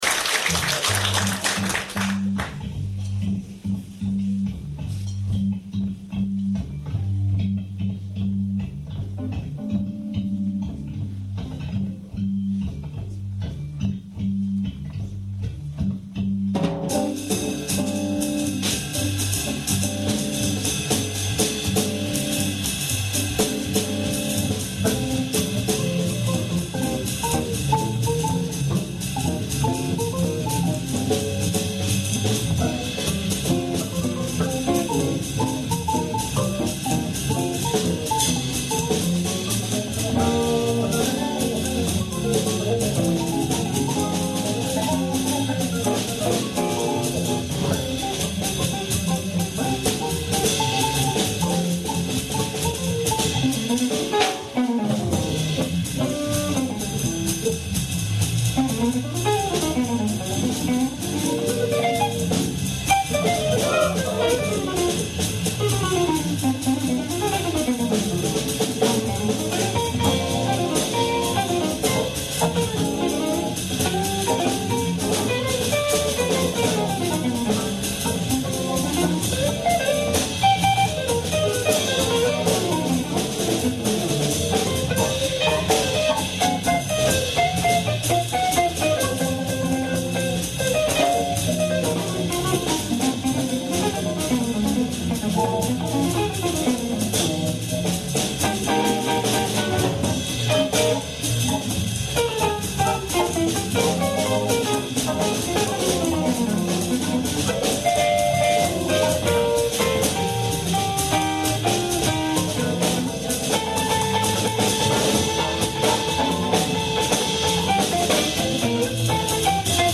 Un gentil spectateur a enregistré la totalité du concert de vendredi dernier sur un minidisc....la qualité n'est pas au rendez vous mais c'est ecoutable
guitare
batterie
La batterie couvre pas mal..mais bon, c'est du live!